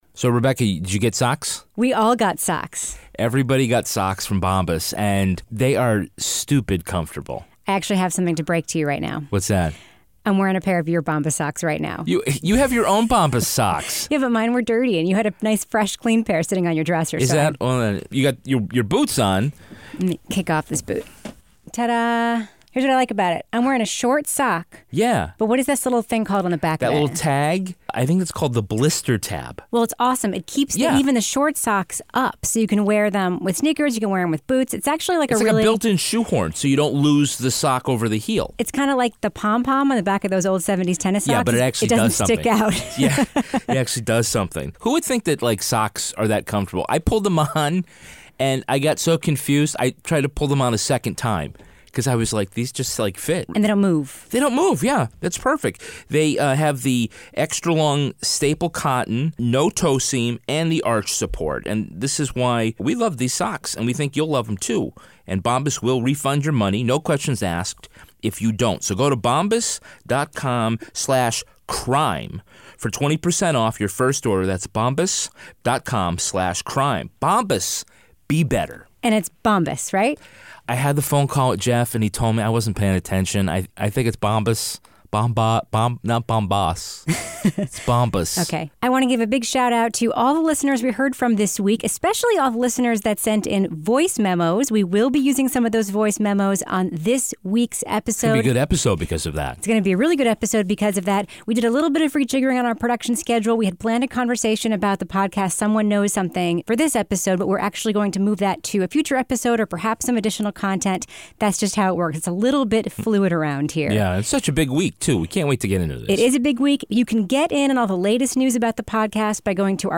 Plus, we listen to some pithy listener voice memos and tackle a juvenile crime of the week.